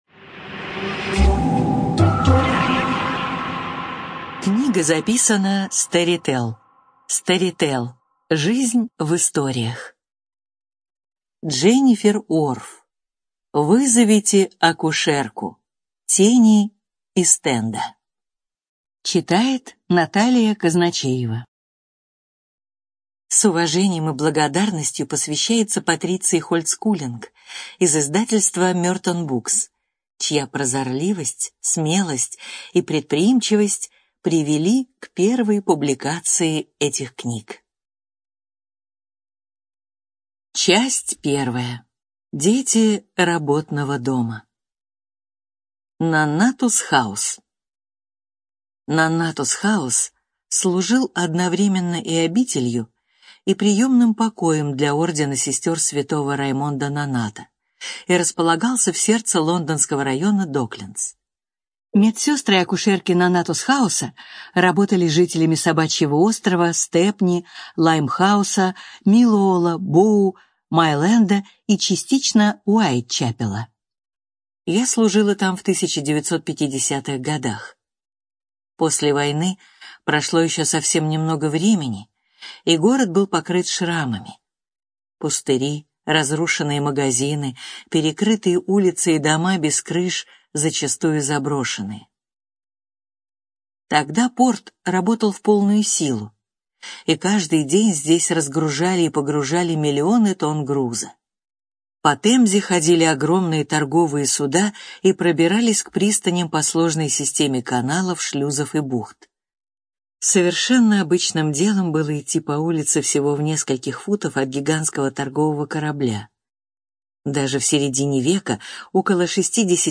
Студия звукозаписиStorytel